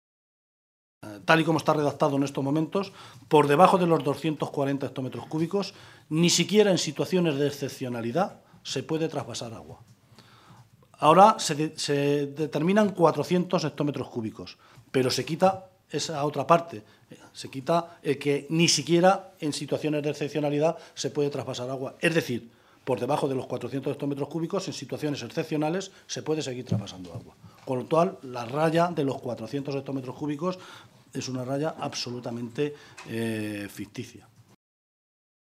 José Luís Martínez Guijarro, portavoz del Grupo Parlamentario Socialista
Guijarro ha hecho esta denuncia esta mañana, en una comparecencia ante los medios de comunicación, en Toledo, en la que ha acusado a Cospedal “de intentar engañarnos otra vez a todos los castellano-manchegos, vendiendo las supuestas bondades del Plan de cuenca oficial del río Tajo, mientras, con opacidad y oscurantismo, está conociendo y consintiendo que el Ministerio y las comunidades autónomas de Murcia y Valencia negocien un documento que perpetúa el trasvase y, a la postre, es más importante que el propio documento sobre el Plan de cuenca que se conoció la semana pasada”.